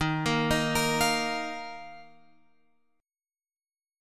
D# Suspended 2nd